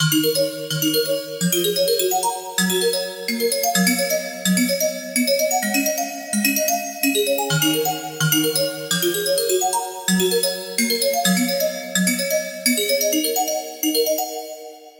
光 Arp Melody 128
Tag: 128 bpm House Loops Synth Loops 4.12 MB wav Key : A